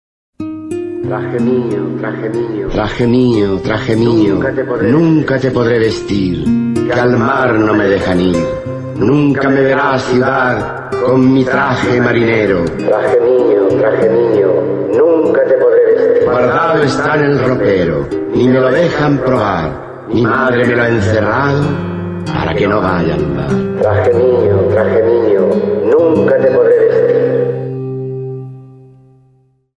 Archivo de sonido con la voz del escritor español Rafael Alberti, quien recita su poema “Ilusión" (Marinero en tierra, 1924).
Se recomienda este recurso para promover un encuentro placentero de los estudiantes con el texto literario, recitado en la voz de su autor.